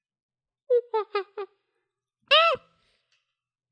Monkey noise (imitation) 3
Category 🐾 Animals
ape chimp chimpanzee monkey sound effect free sound royalty free Animals